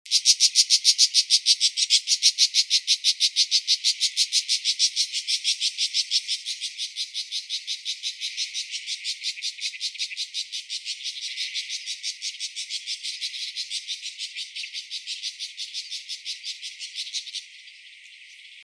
62-4紅尾伯勞4g衛武營2012apr1.WAV
紅尾伯勞(普通亞種) Lanius cristatus lucionensis
錄音地點 高雄市 鳳山區 衛武營
錄音環境 公園樹上
行為描述 鳴叫 錄音器材 錄音: 廠牌 Denon Portable IC Recorder 型號 DN-F20R 收音: 廠牌 Sennheiser 型號 ME 67